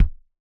IBI Kick.wav